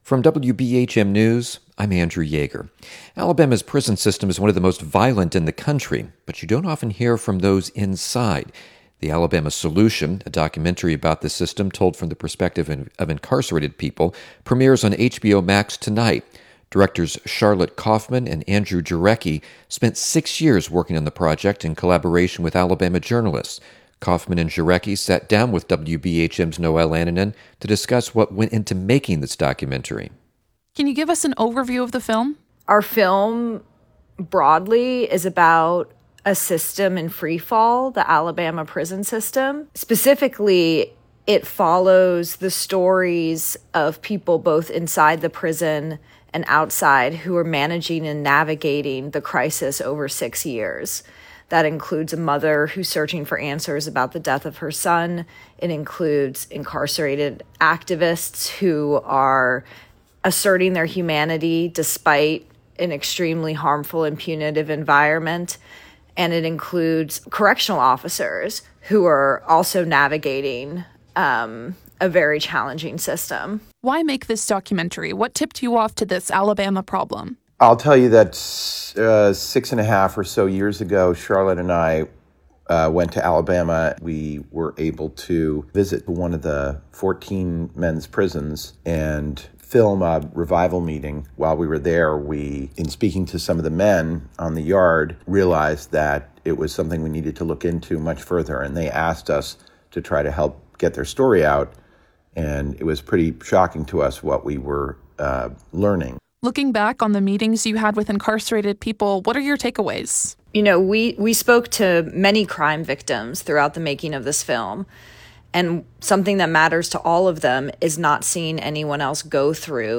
This interview was edited for length and clarity.